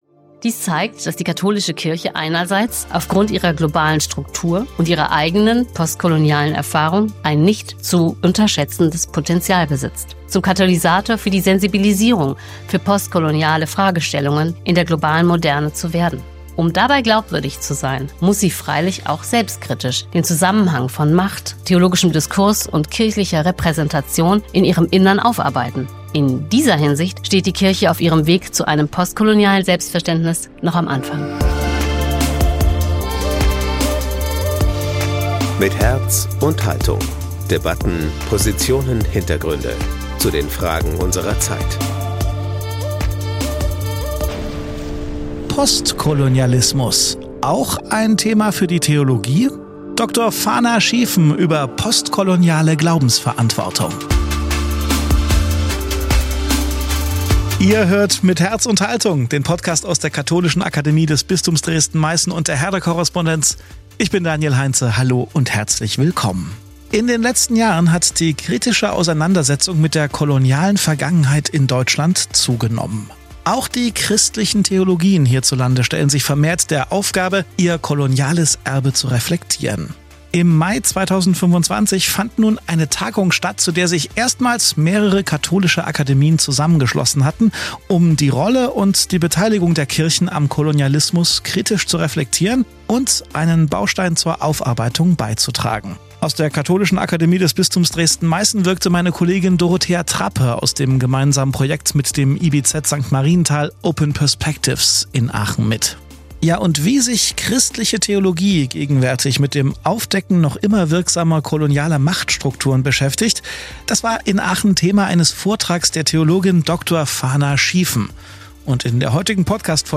Panel Beitrag aus einer Tagung in Aachen